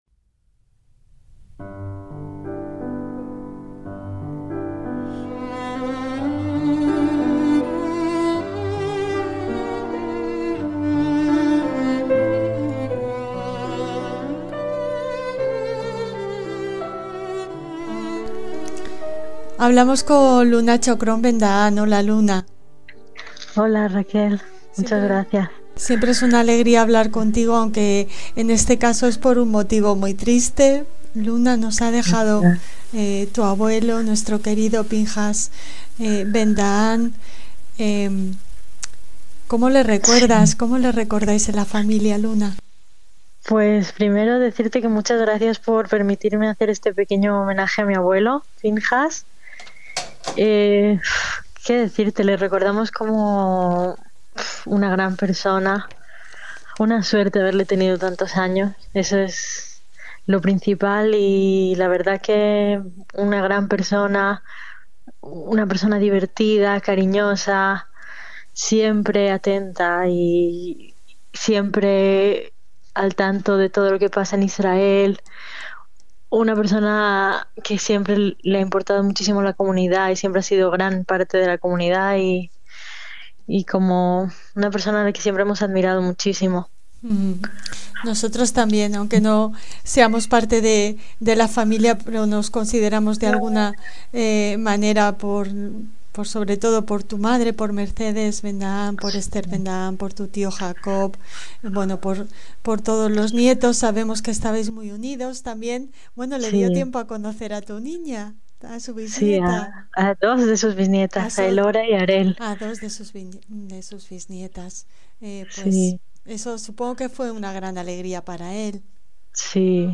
un breve reportaje